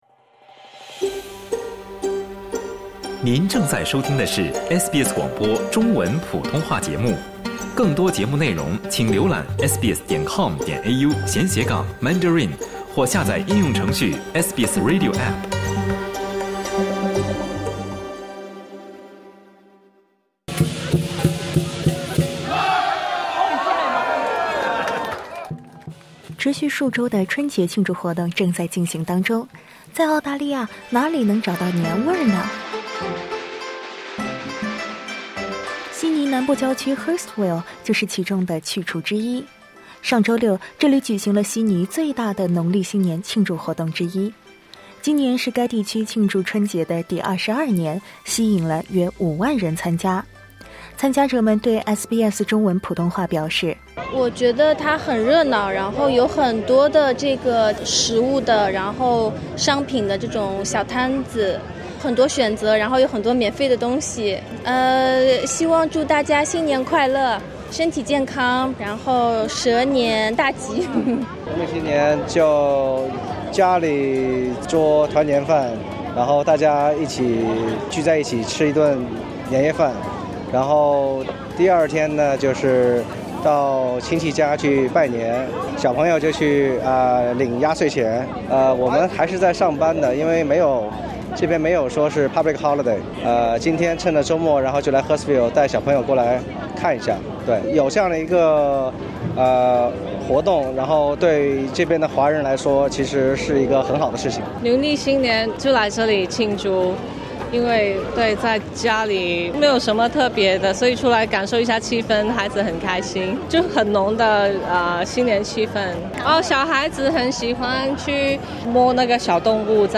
Credit: Georges River Council 在节日上，新州州长柯民思向华人社区传达了祝福。
博格市长河刘娜心副市长还用三种语言向华人社区传递新春祝福。